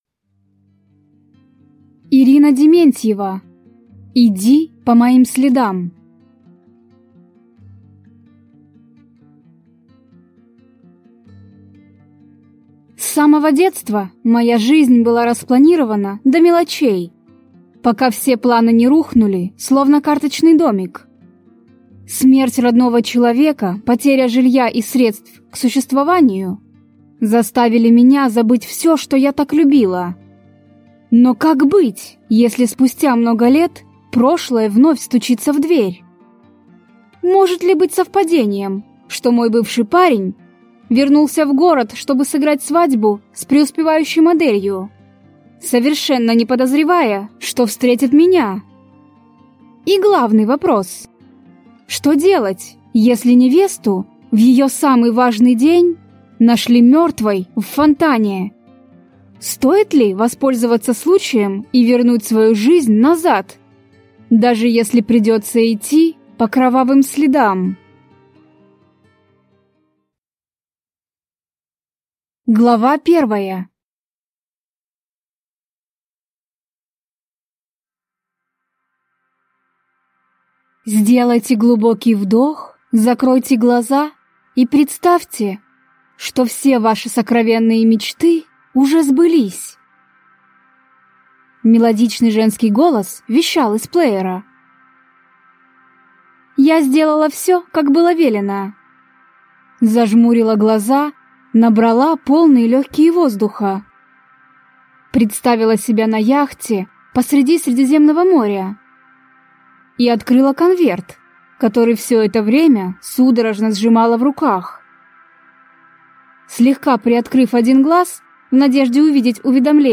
Аудиокнига Иди по моим следам | Библиотека аудиокниг
Прослушать и бесплатно скачать фрагмент аудиокниги